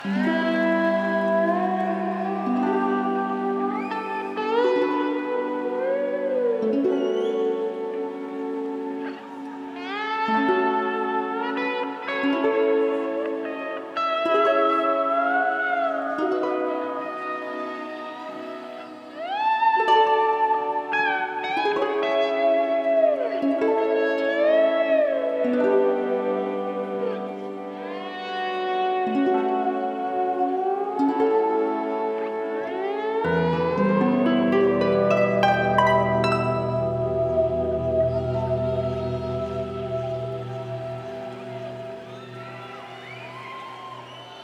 Жанр: Rock
Стиль: Prog Rock
Издание на двух CD представляет 23 трека с этого тура.